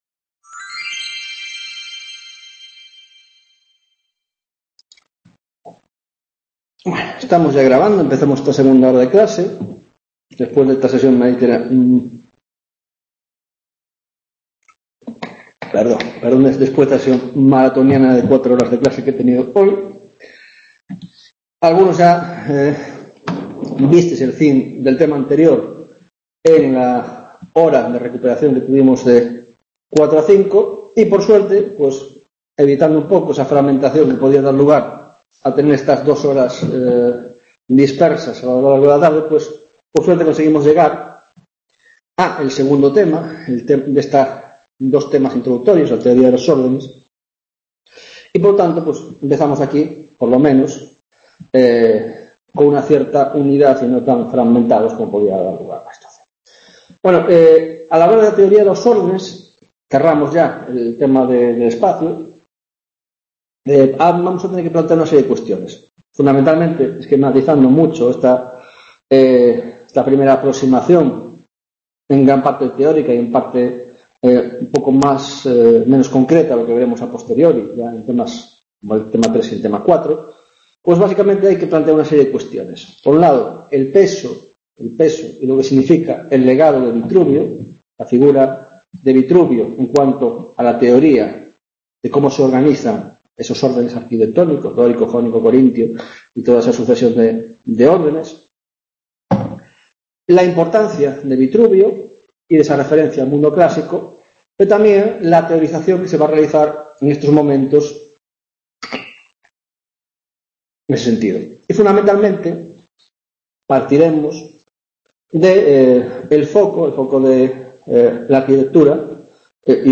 3ª Tutoría de Órdenes y Espacio en la Arquitectura del XV - XVIII - Teoría de los Ordenes - 1 Teoría de los Órdenes en Italia, 1ª parte